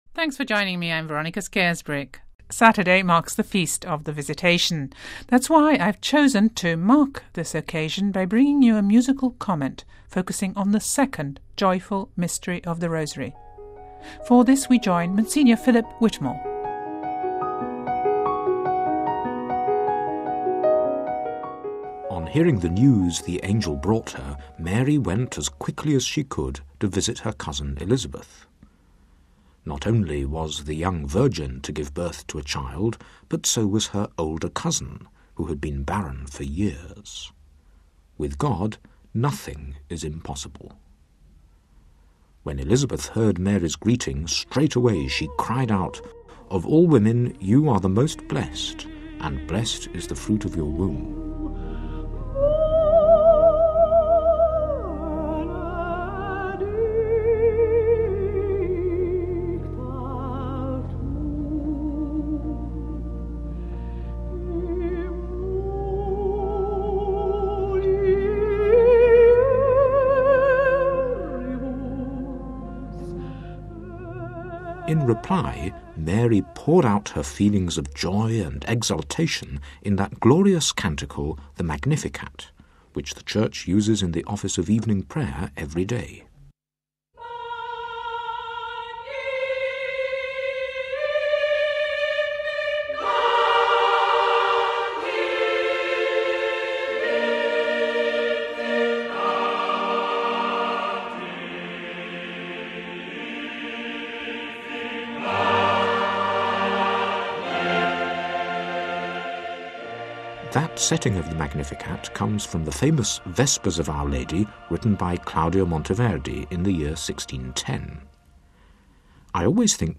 weaves in Monteverdi and Bach for the occasion...